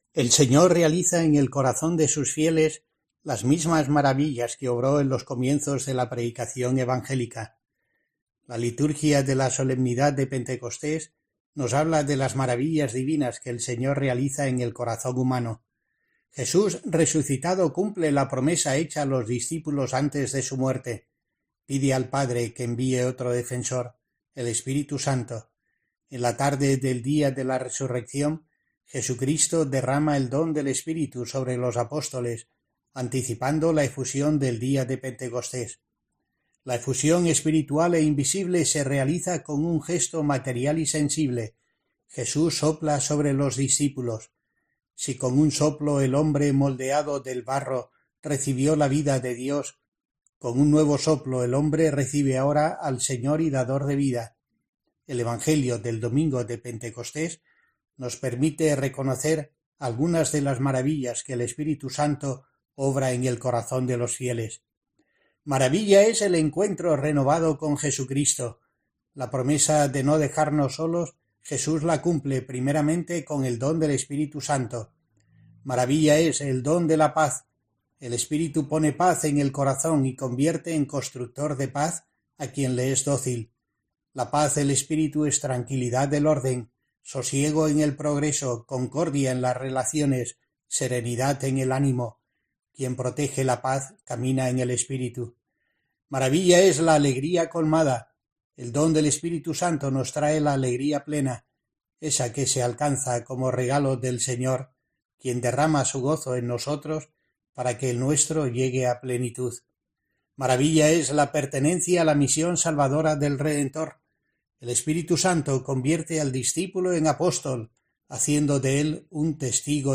El obispo de Asidonia-Jerez reflexiona sobre la solemnidad de Pentecostés que se celebra este domingo y tiene un recuerdo para cuantos diocesanos lo festejan en la conocida Romería